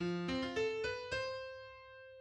The movement ends as peacefully as it started, but we are quickly snapped out of the reverie with the thunderous timpani thump that launches the lively scherzo into action.